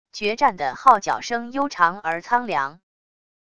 决战的号角声悠长而苍凉wav音频